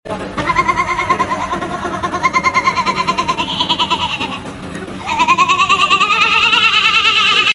Hehehehehe hehehehehehehehehe...
You Just Search Sound Effects And Download. tiktok sound effects funny Download Sound Effect Home